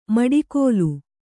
♪ maḍikōlu